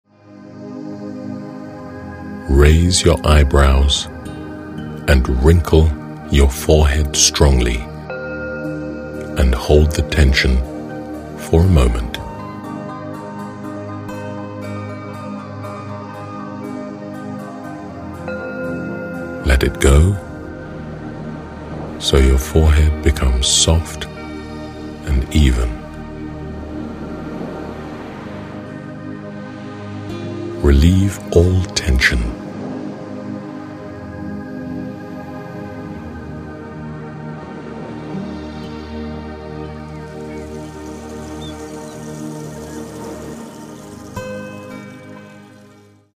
This Audiobook is a guide for your self-studies and learning.